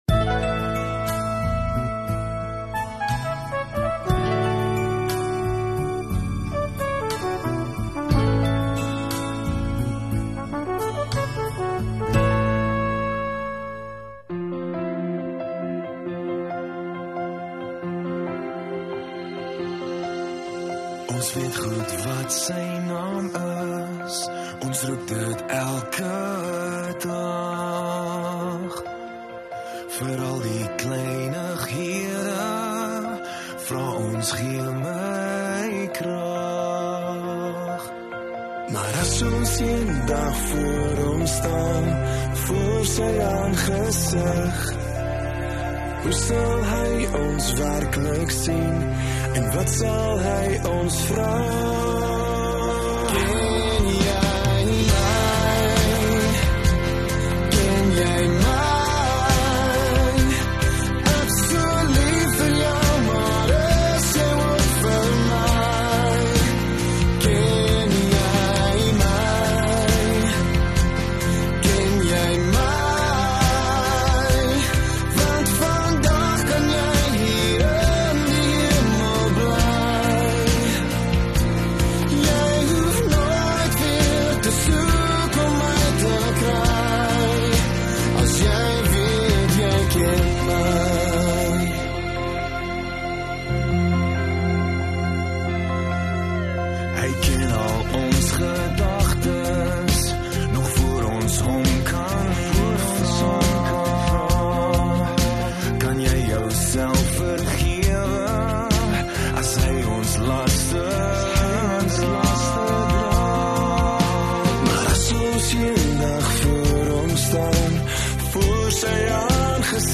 25 Jul Vrydag Oggenddiens